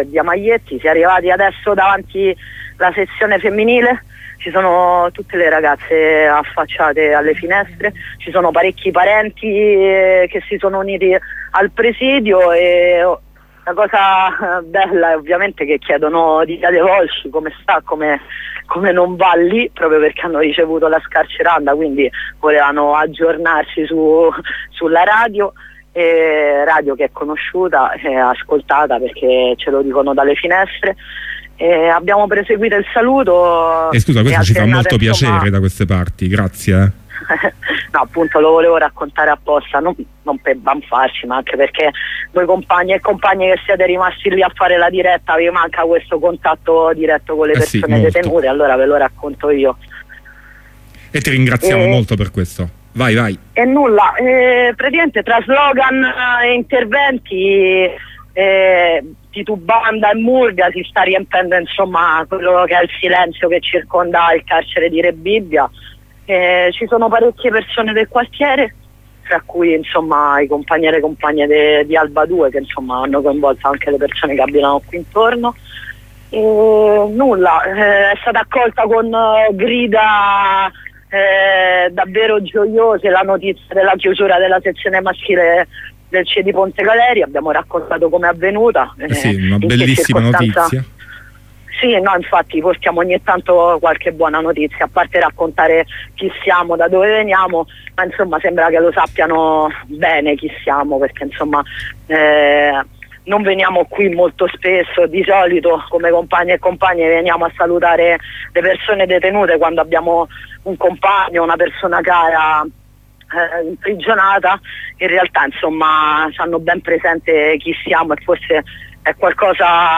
Prosegue il giro intorno alle mura con il saluto ai prigionieri e prigioniere .